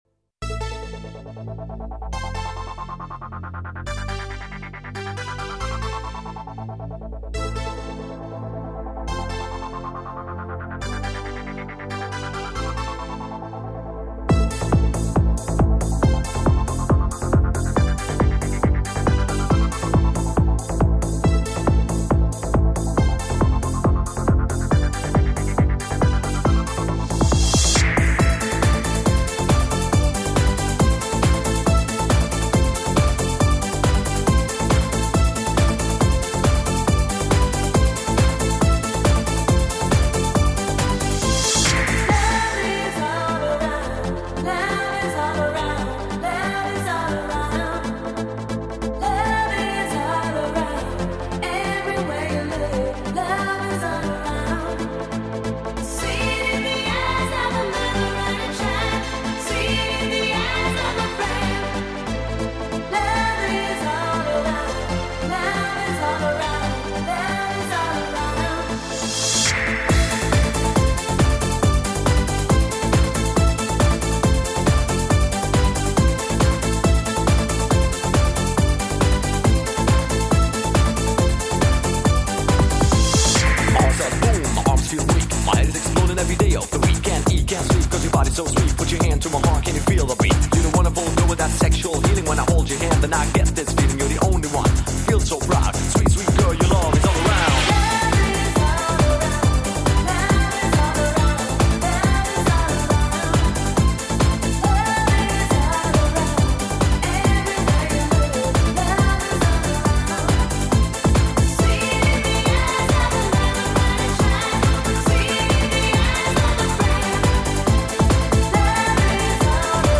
EuroDance 90-х